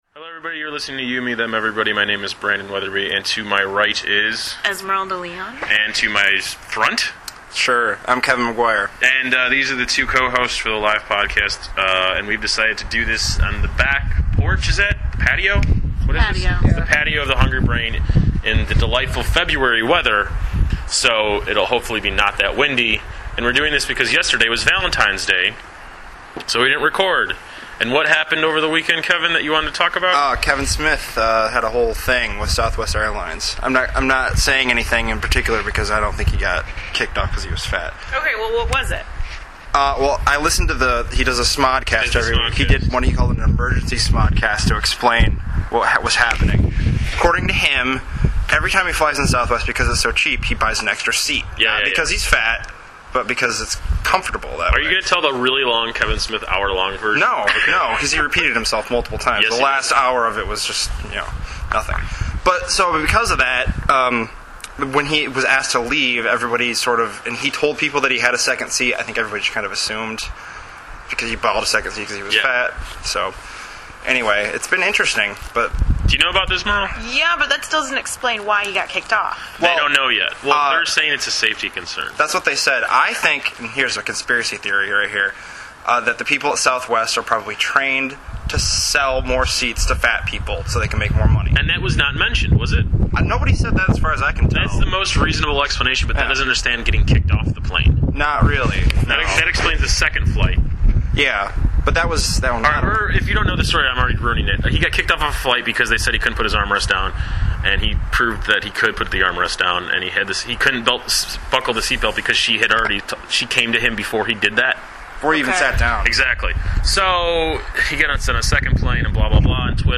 Recorded over five hours outside of the Hungry Brain.